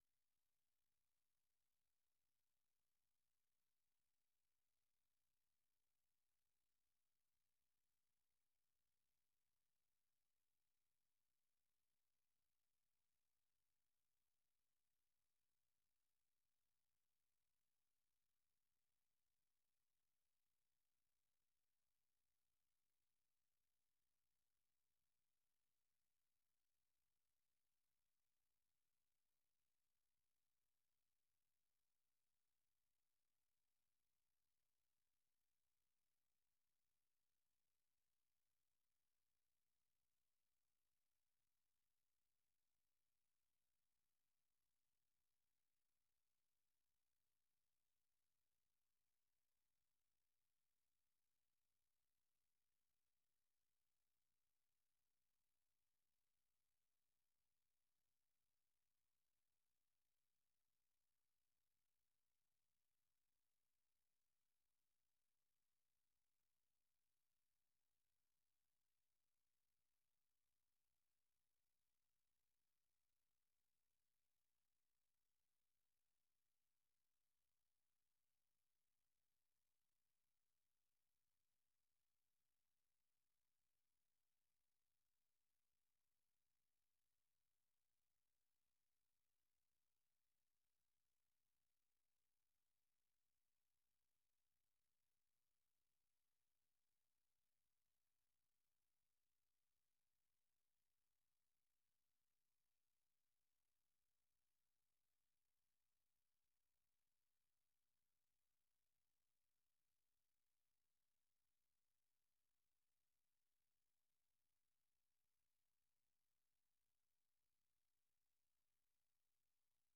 Listen Live - 粵語廣播 - 美國之音